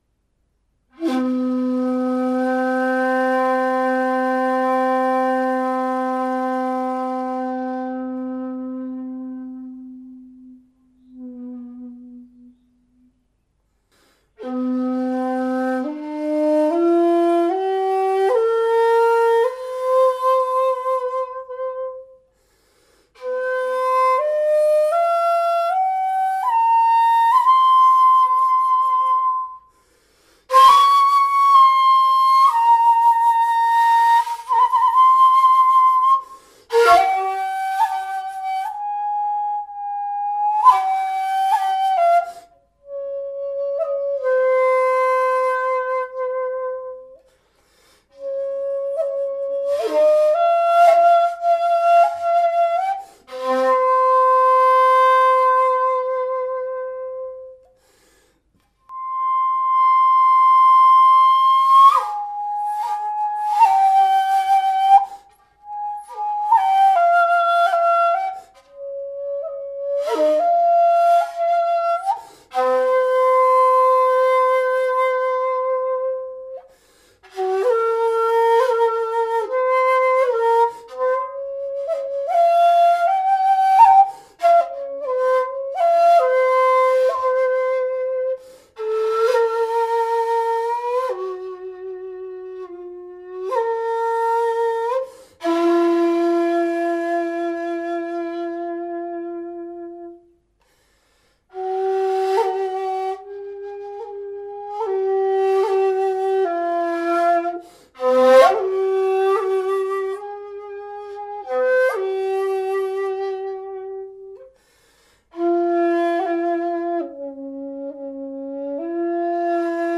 Chikudo Ji-nashi shakuhachi 2.0 in C | Atelier Chikudo
Ji-nashi shakuhachi